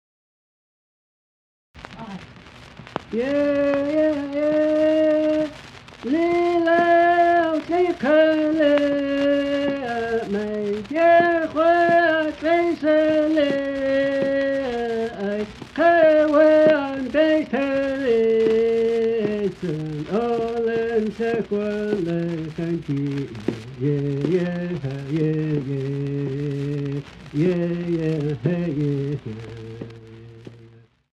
Chapter 14. First Nations Music of the Pacific Northwest Coast:
Love songs are included in the general category of Social Songs which are not part of the Potlatch musical complex. The melodic contour is descending. There is a typical opening interval, the ascending large 2nd.
Cape Mudge, British Columbia